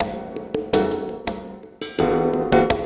Percussion 10.wav